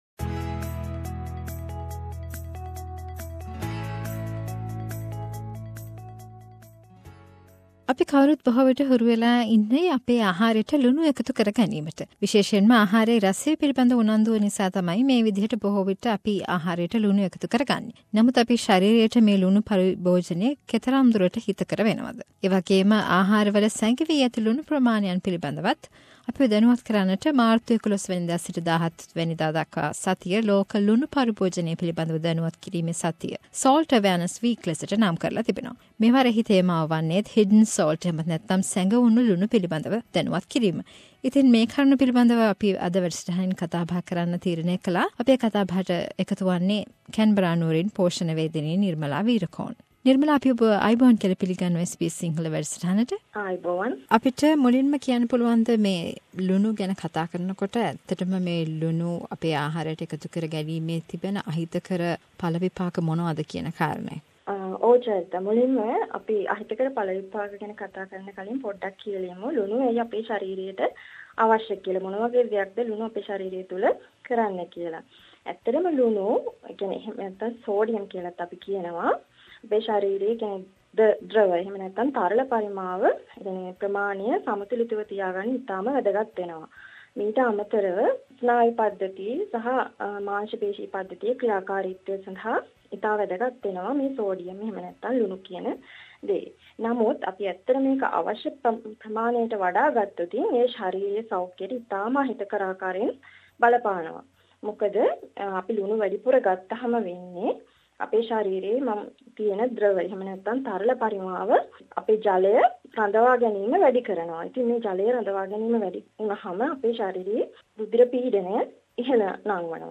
A discussion on salt usage to mark Salt awareness week